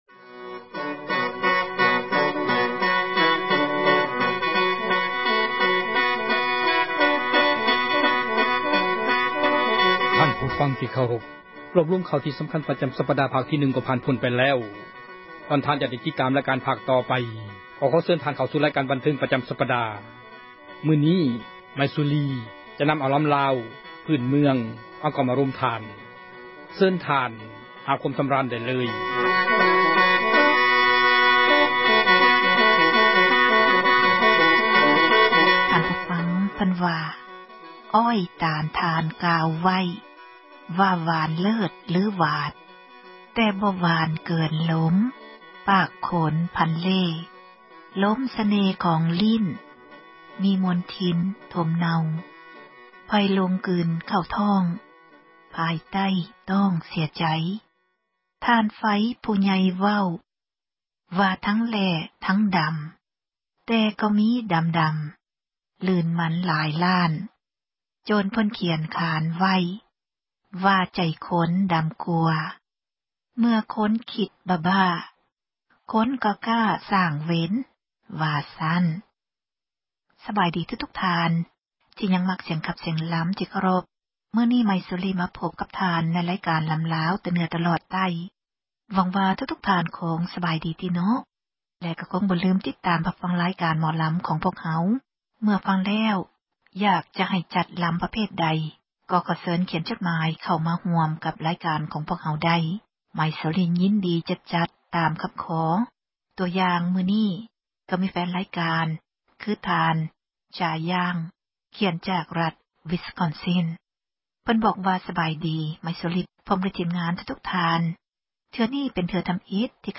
ສິລປະ ການຂັບລໍາ ຂອງ ເຊື້ອສາຍ ລາວ ໃນແຕ່ລະ ຊົນເຜົ່າ ແຕ່ລະ ພື້ນເມືອງ.